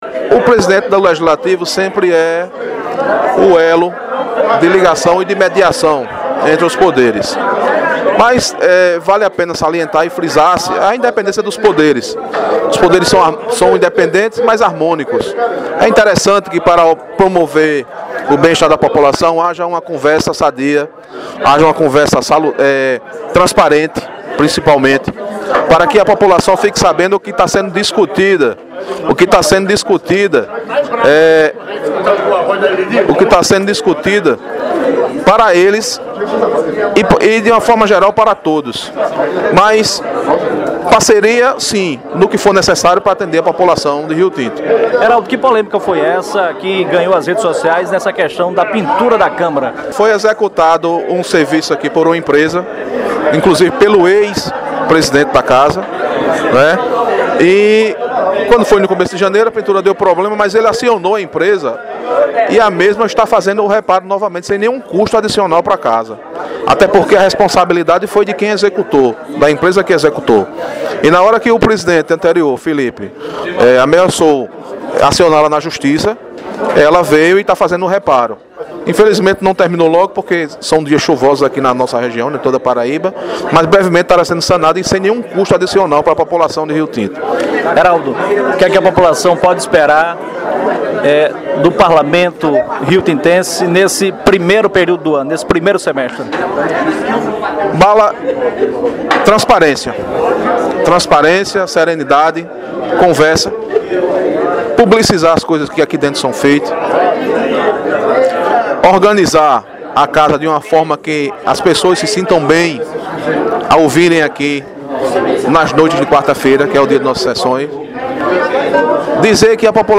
VEREADOR-ERALDO-CALIXTO.mp3